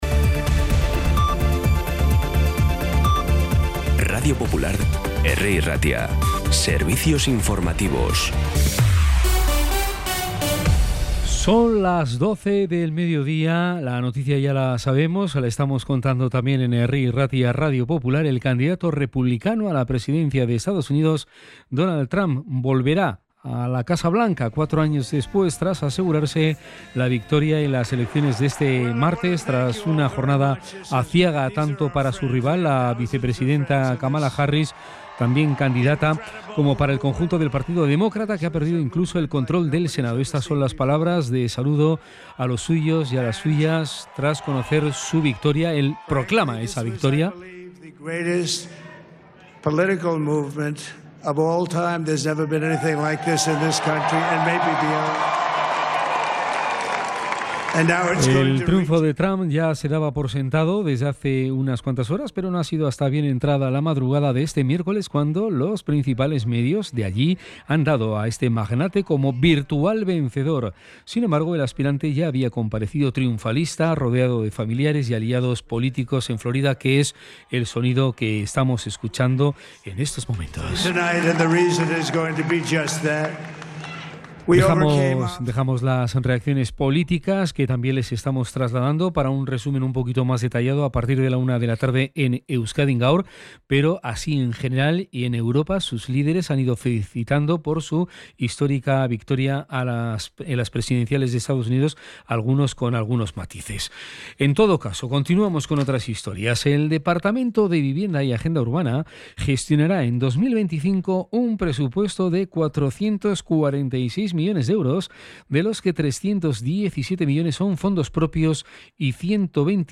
Las noticias de Bilbao y Bizkaia del 6 de noviembre a las 12